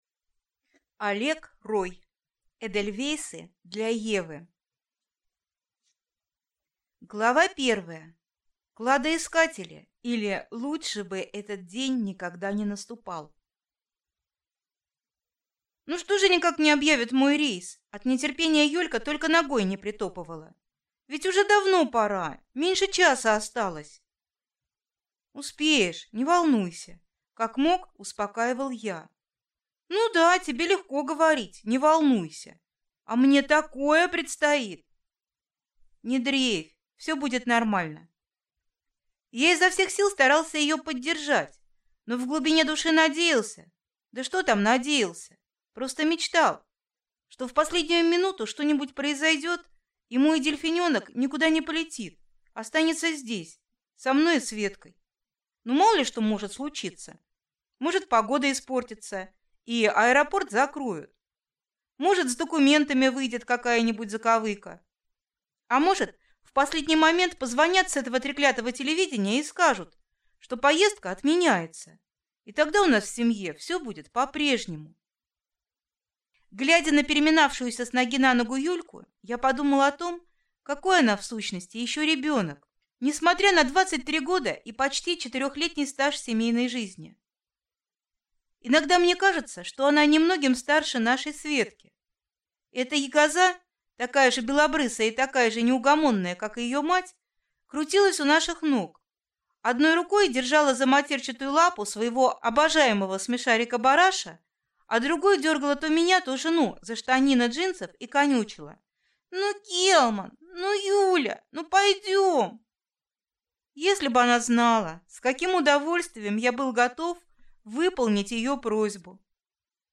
Аудиокнига Эдельвейсы для Евы | Библиотека аудиокниг
Прослушать и бесплатно скачать фрагмент аудиокниги